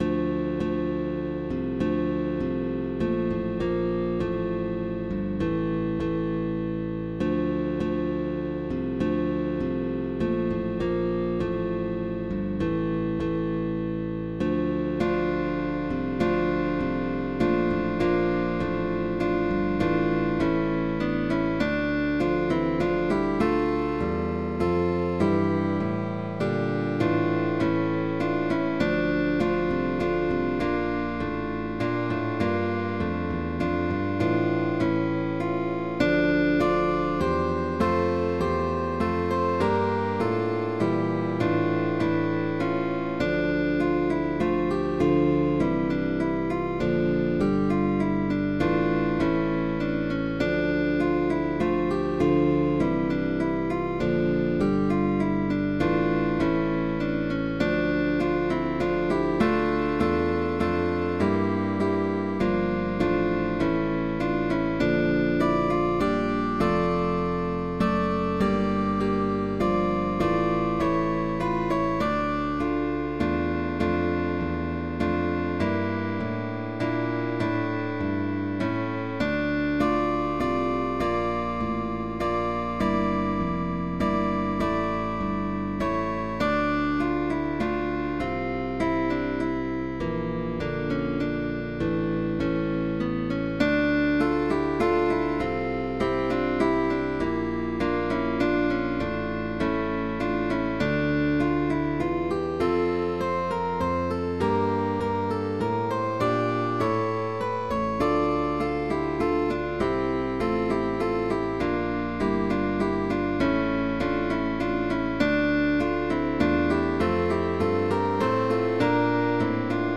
cuarteto de guitarras
CUARTETO DE GUITARRAS
Etiqueta: Barroco